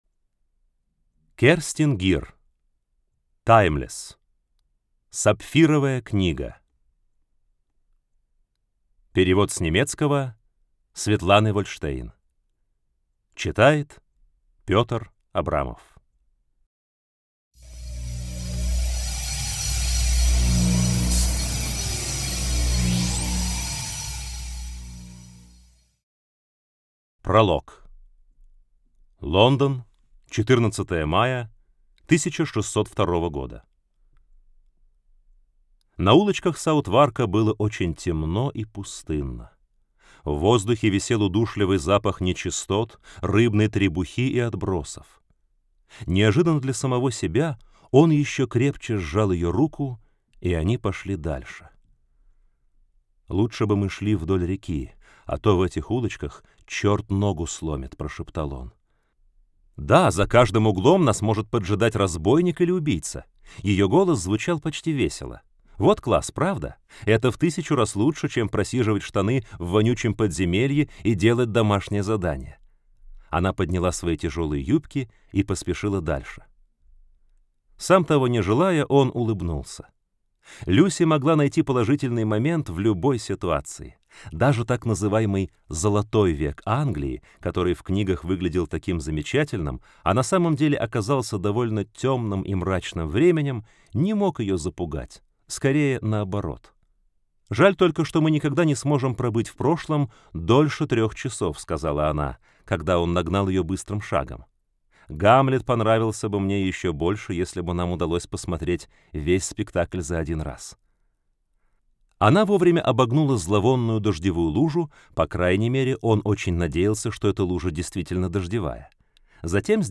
Аудиокнига Сапфировая книга - купить, скачать и слушать онлайн | КнигоПоиск